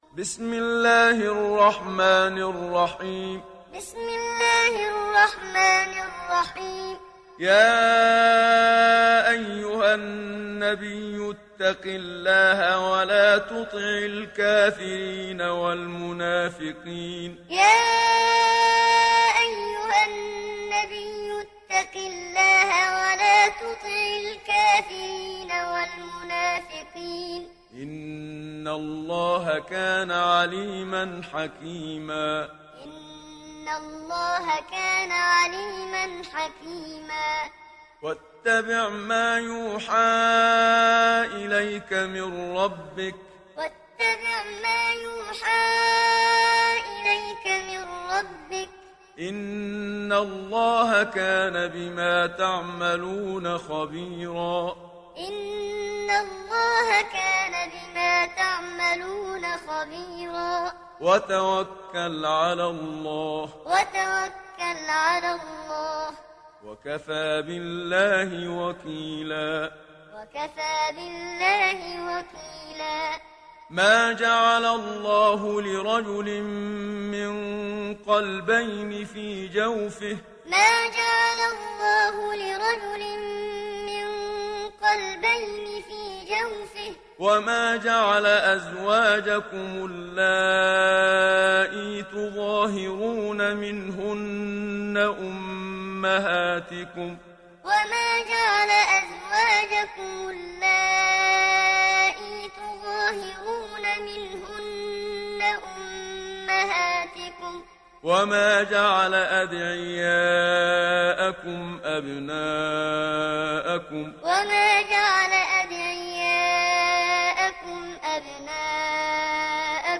دانلود سوره الأحزاب mp3 محمد صديق المنشاوي معلم روایت حفص از عاصم, قرآن را دانلود کنید و گوش کن mp3 ، لینک مستقیم کامل